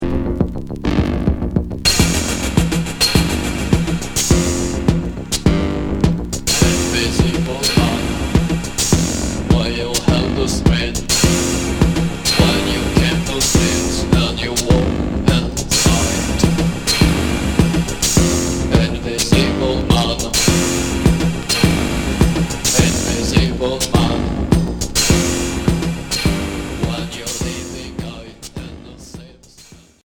Cold wave indus